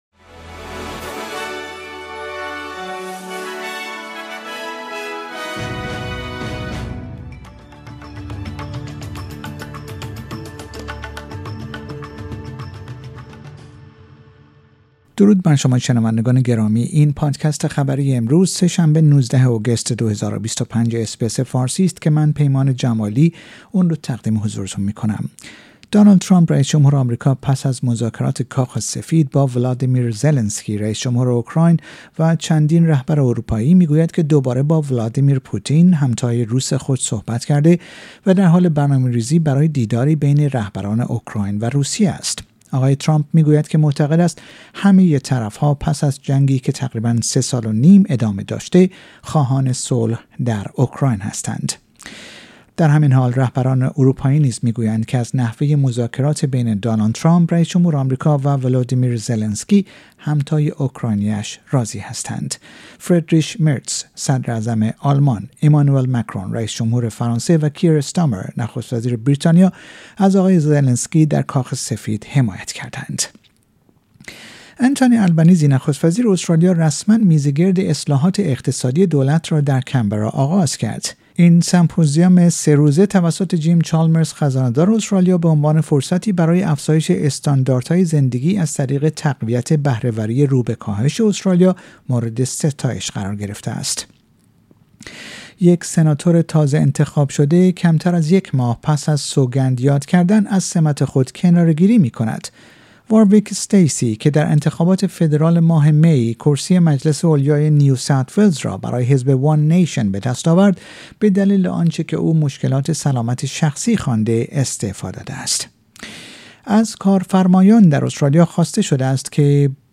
در این پادکست خبری مهمترین اخبار روز سه شنبه ۱۹ آگوست ارائه شده است.